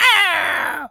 bird_vulture_hurt_02.wav